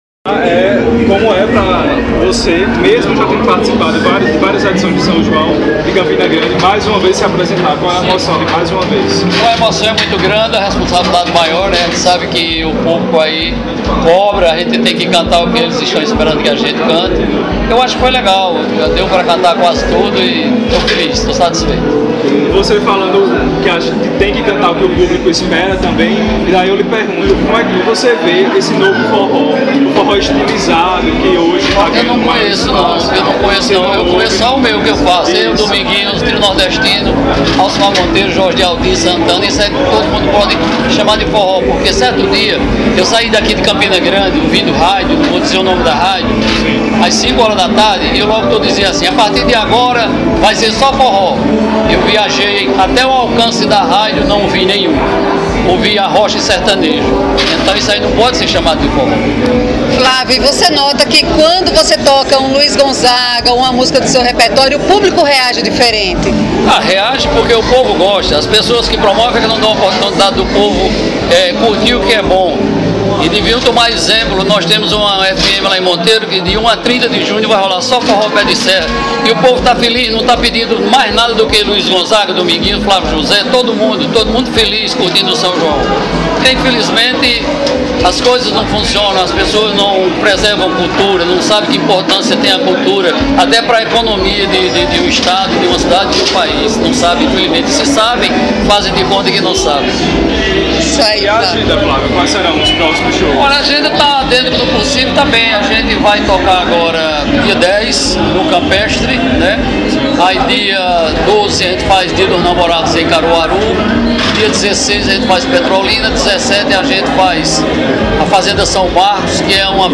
Em entrevista ao Repórter Junino, Flávio José fala sobre sua participação nesta edição da festa.
Entrevista-com-Flávio-José.mp3